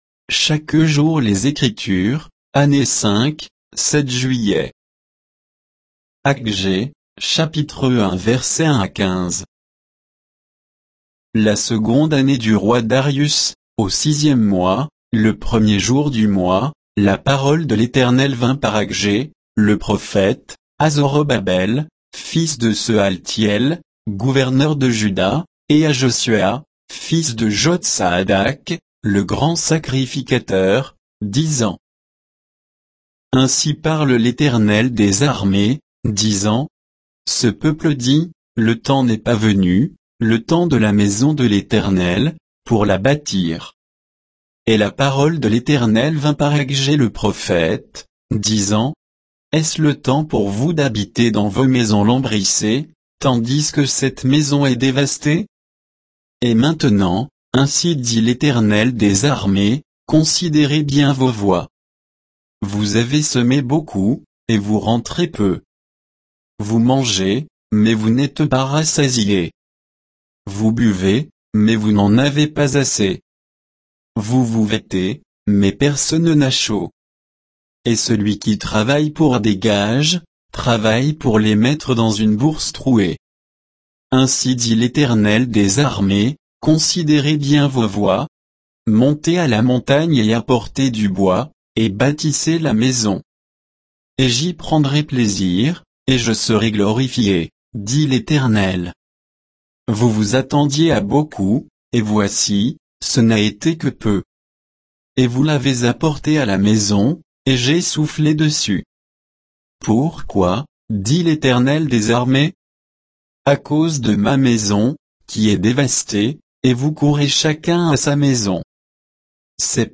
Méditation quoditienne de Chaque jour les Écritures sur Aggée 1